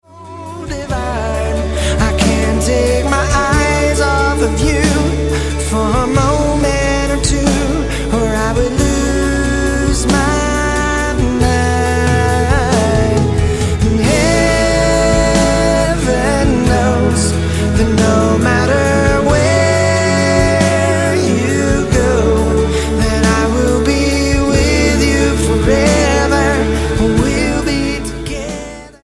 American singer songwriter
Pop